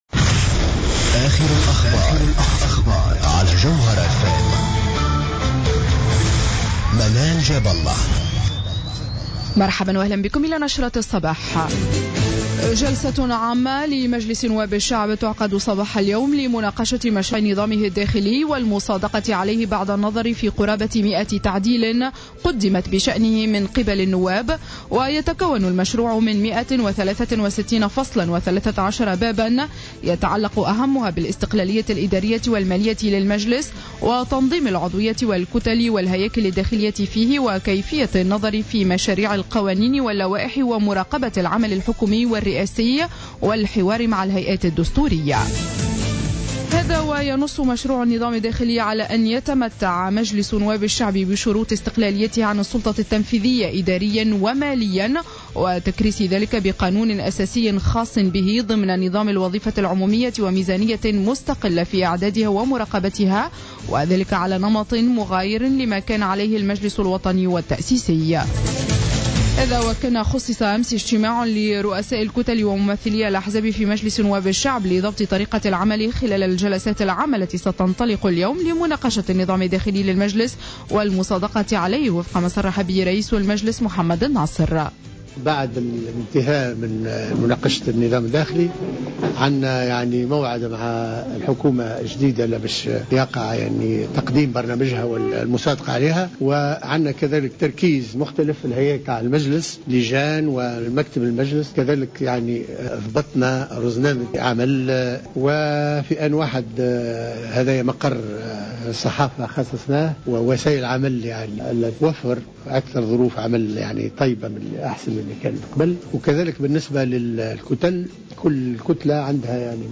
نشرة أخبار السابعة صباحا ليوم الثلاثاء 20-01-15